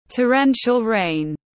Torrential rain /təˈren.ʃəl reɪn/
Torrential-rain.mp3